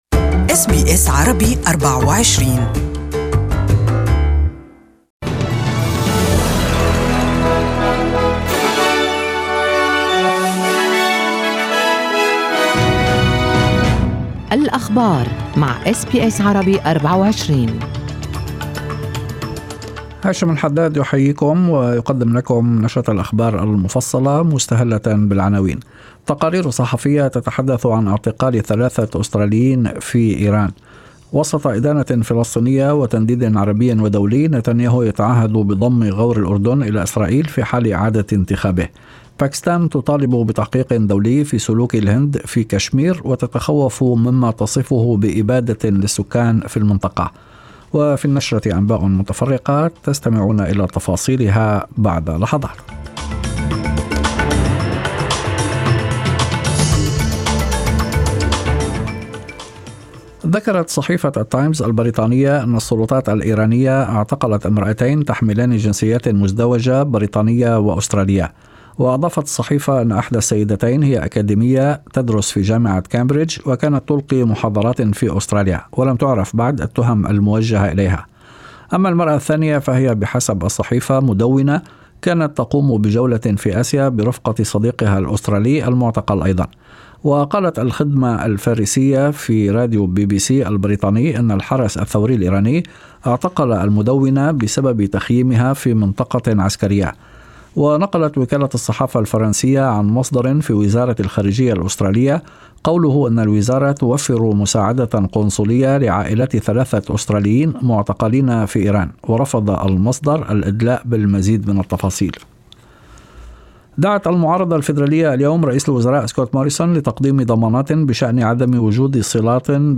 Evening News: ‘I am a proud Australian’: Gladys Liu admits to past China links